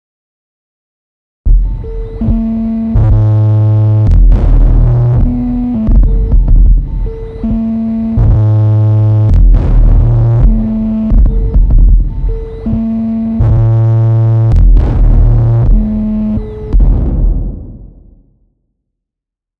При разных значениях этого параметра можно получать довольно разное звучание на одних и тех же настройках: